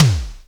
TOM SOFT T00.wav